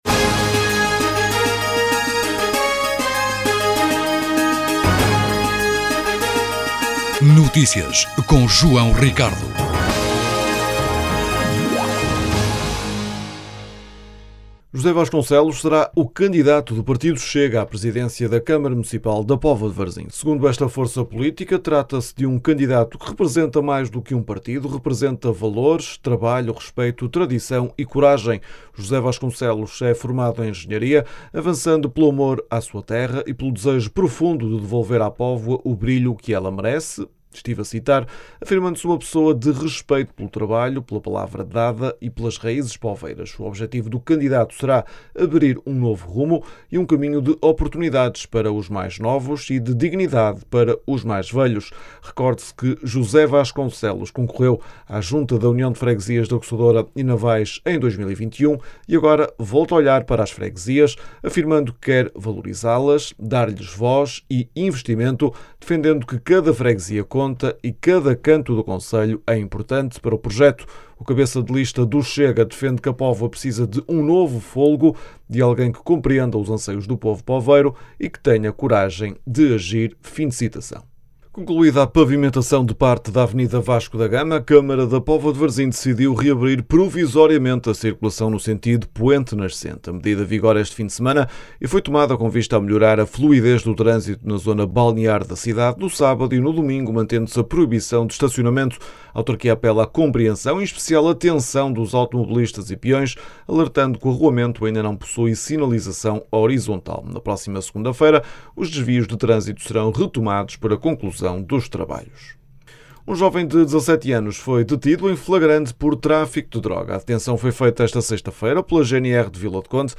O edil Vítor Costa destaca os elementos simbólicos que serão mantidos, nomeadamente a cor rosa do edifício e a criação de um espaço dedicado à história do local.
As declarações podem ser ouvidas na edição local.